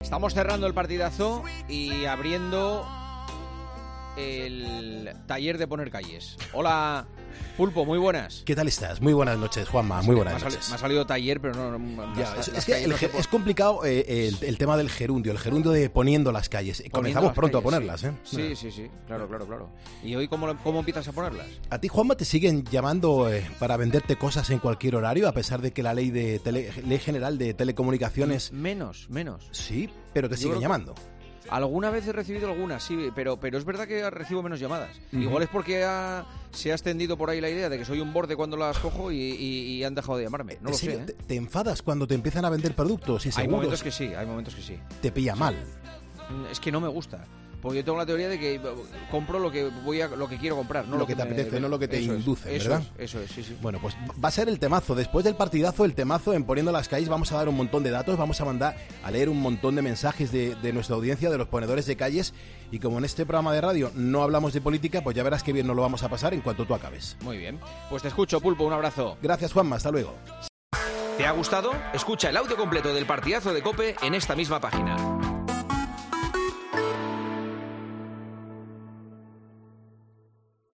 AUDIO: El presentador de El Partidazo de COPE le confesó a Carlos Moreno 'El Pulpo' su experiencia con las compañías que te llaman a deshora